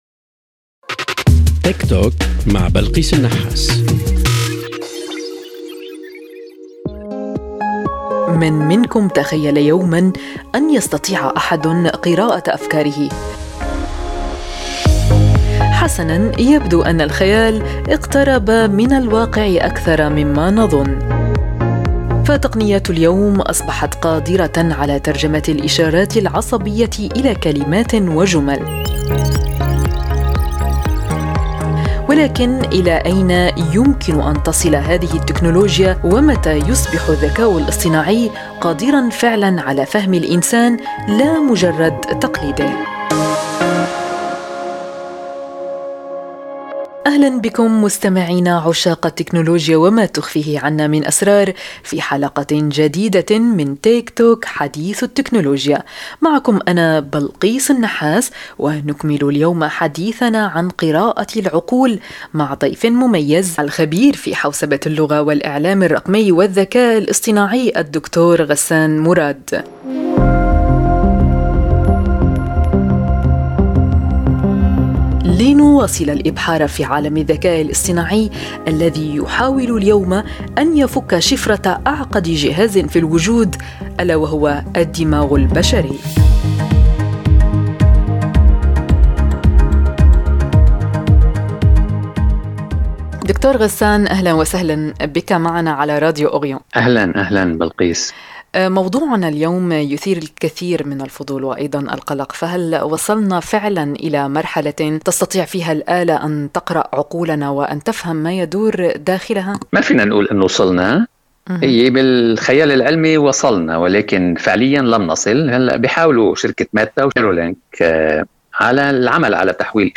في هذا الحوار الخاص